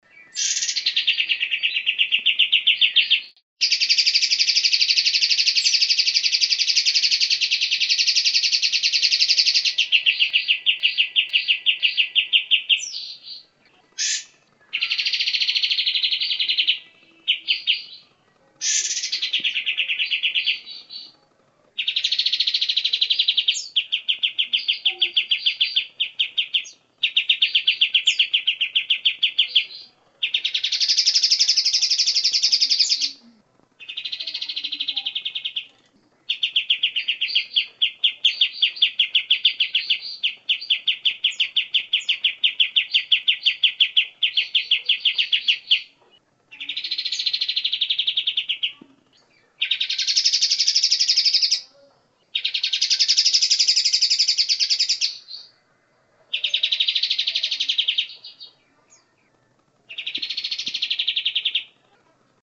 Suara Burung Kapas Tembak